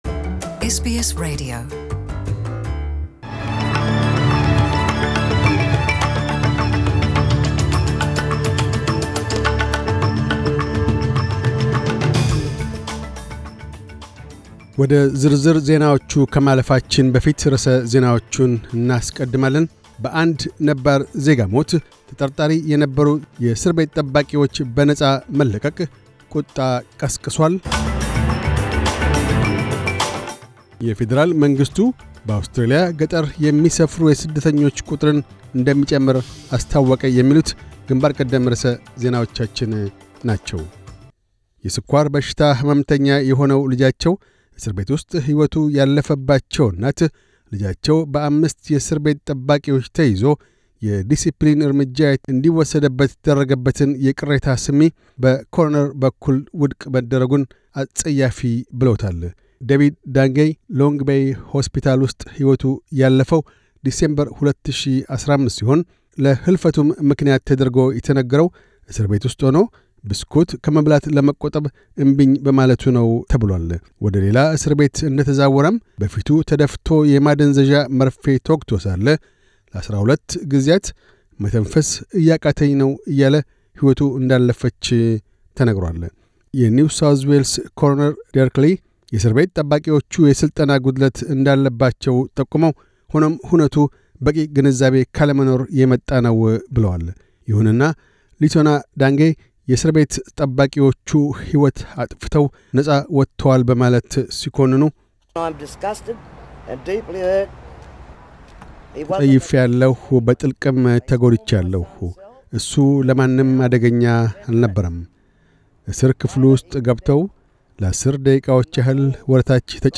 News Bulletin 2211